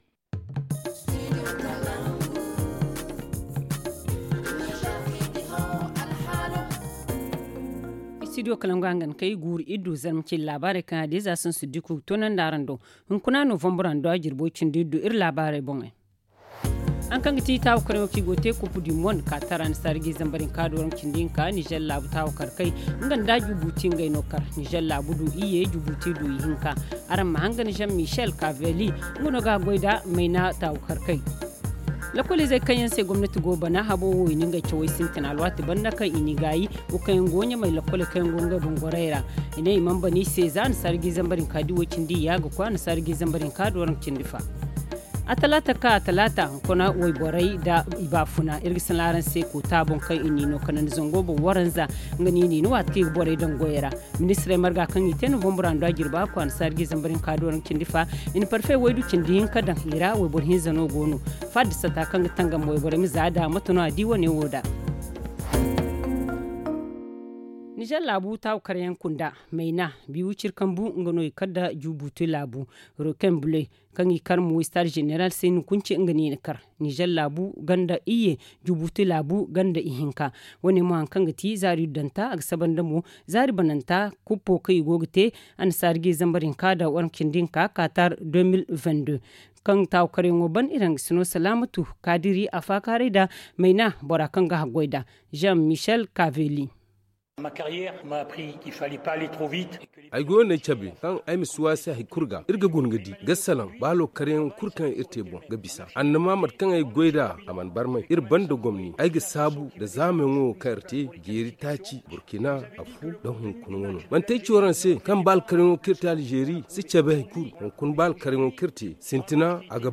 Le journal du 16 novembre 2021 - Studio Kalangou - Au rythme du Niger